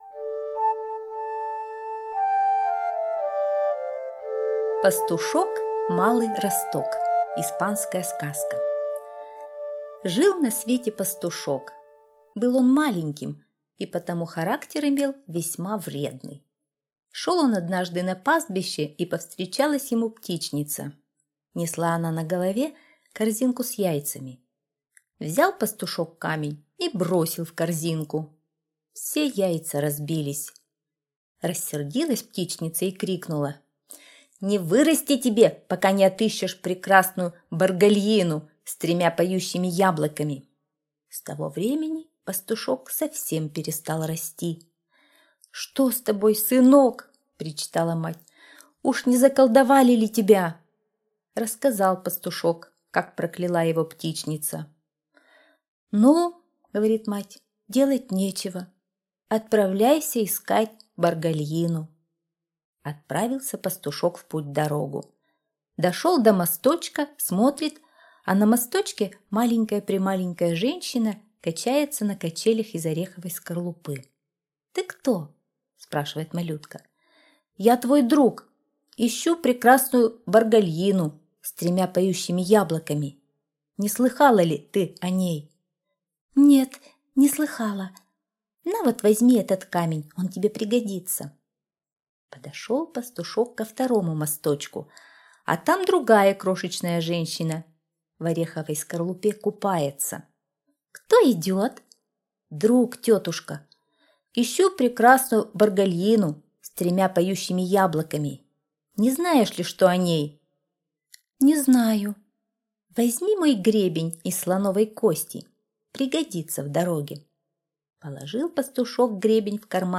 Пастушок-малый росток - испанская аудиосказка - слушать онлайн